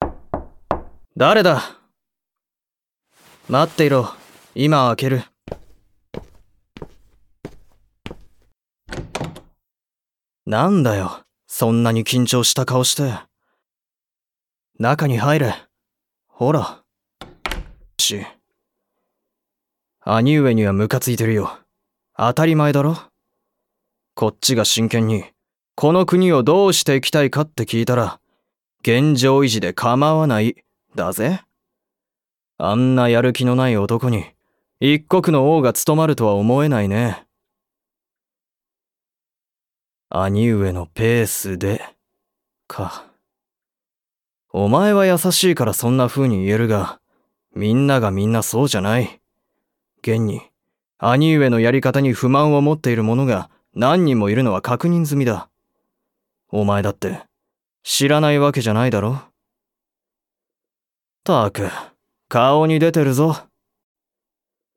[オーディオブック] 王弟シリーズ「下剋上！俺の方が優秀だ！！」
王弟として生まれ育った人物を主人公とした様々な世界観のオリジナルシチュエーションドラマ